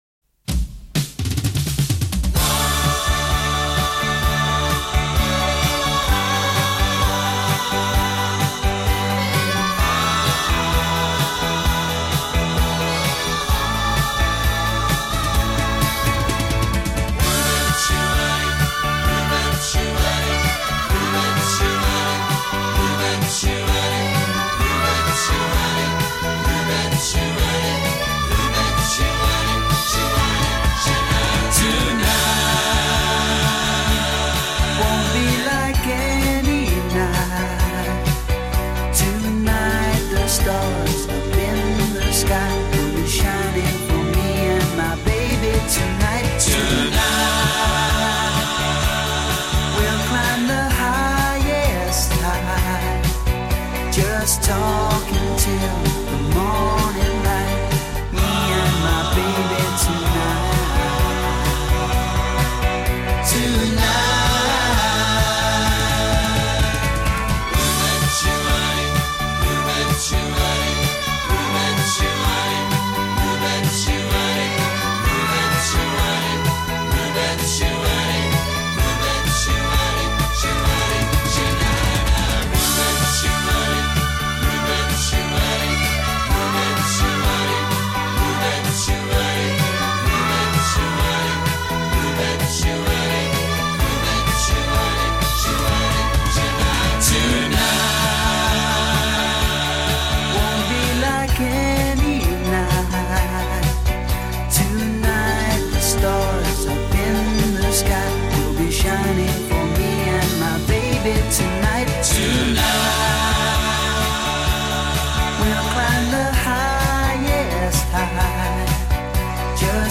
banda inglesa de pop glam